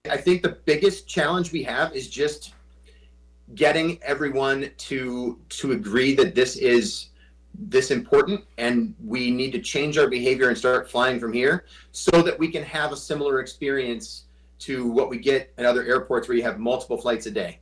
Fly DBQ Holds Virtual Town Hall Meeting
Dubuque Mayor Brad Cavanaugh.